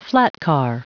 Prononciation du mot flatcar en anglais (fichier audio)
Vous êtes ici : Cours d'anglais > Outils | Audio/Vidéo > Lire un mot à haute voix > Lire le mot flatcar
Prononciation du mot : flatcar